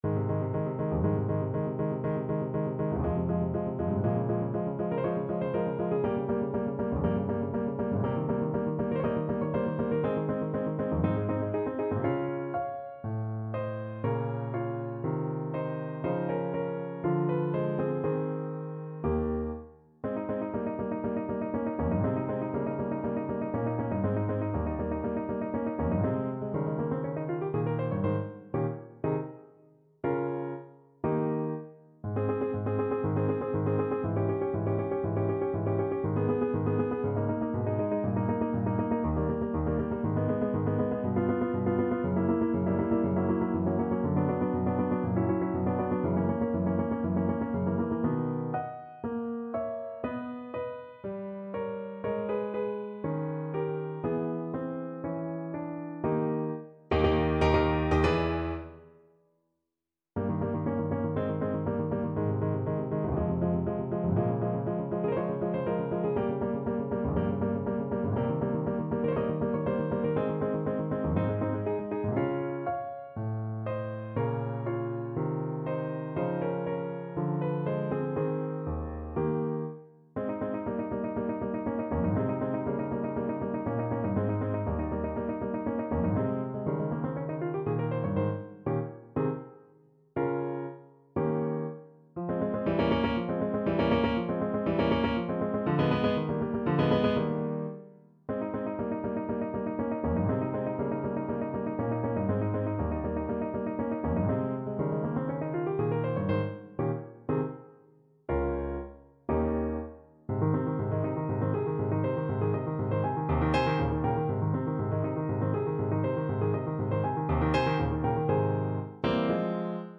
Play (or use space bar on your keyboard) Pause Music Playalong - Piano Accompaniment Playalong Band Accompaniment not yet available transpose reset tempo print settings full screen
Ab major (Sounding Pitch) F major (Alto Saxophone in Eb) (View more Ab major Music for Saxophone )
=120 Andante
Classical (View more Classical Saxophone Music)